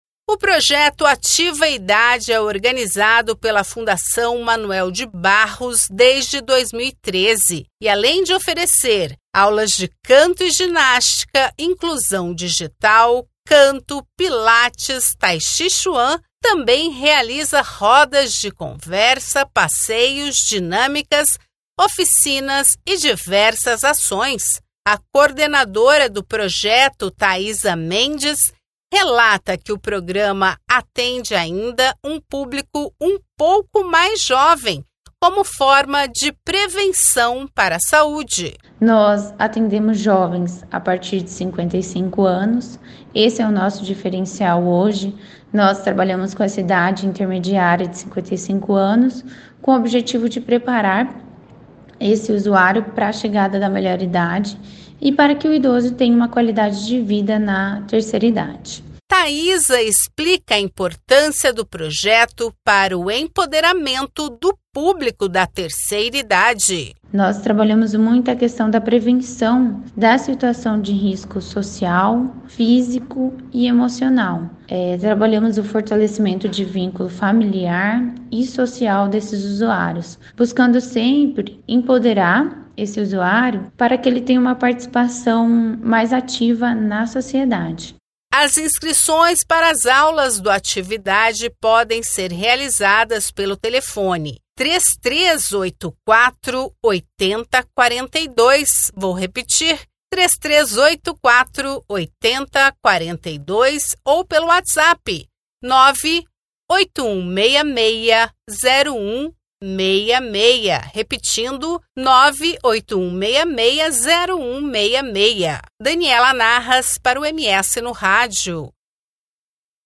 Saiba mais na reportagem de